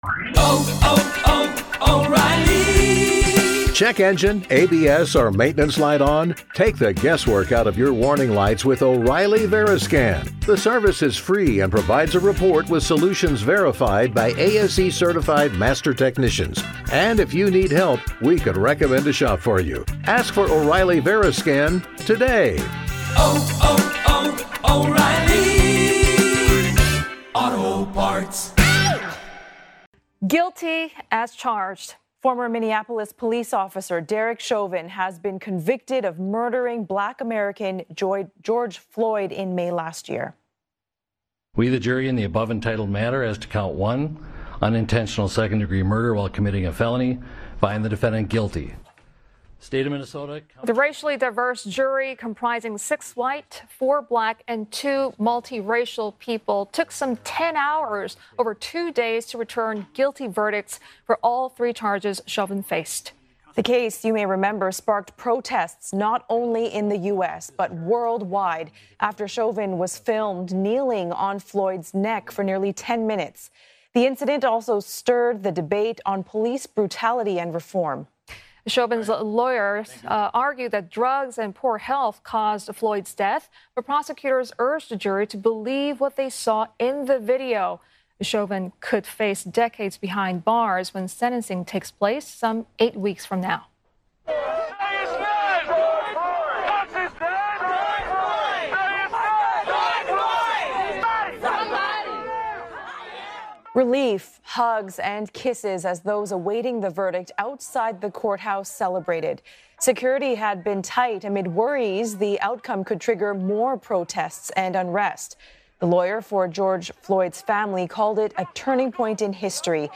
live report